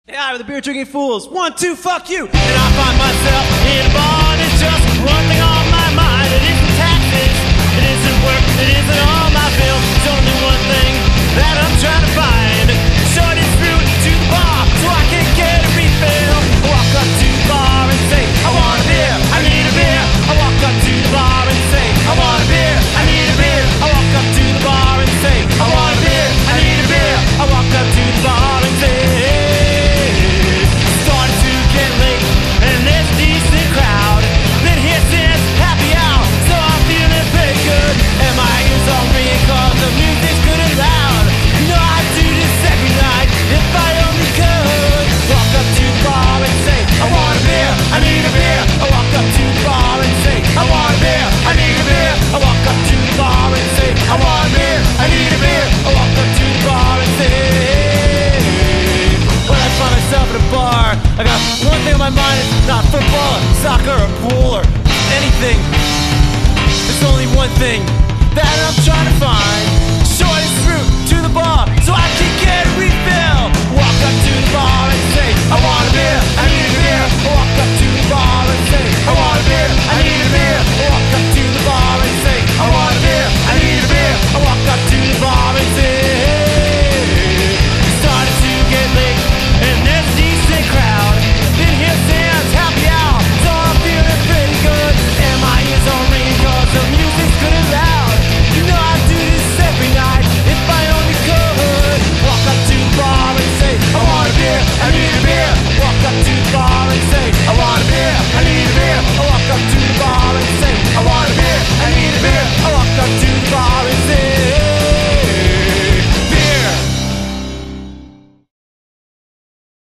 Vocals, Tin Whistle
Guitar, Vocals
Bass
Drums